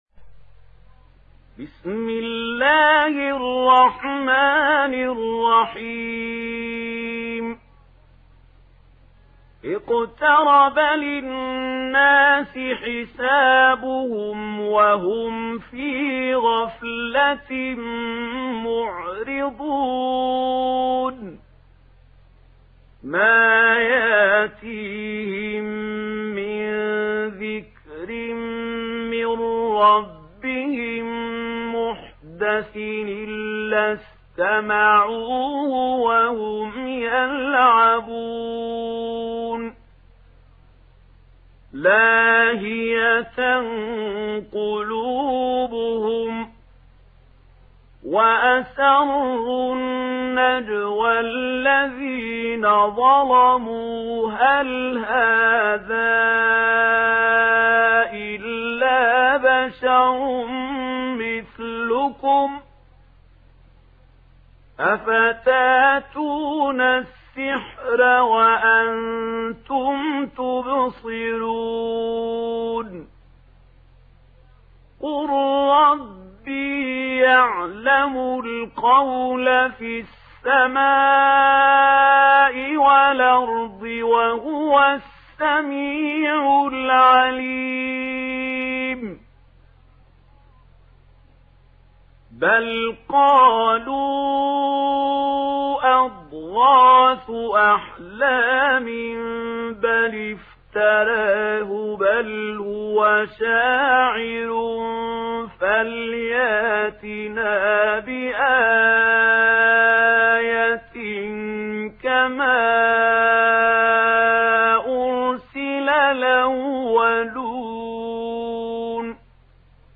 دانلود سوره الأنبياء mp3 محمود خليل الحصري روایت ورش از نافع, قرآن را دانلود کنید و گوش کن mp3 ، لینک مستقیم کامل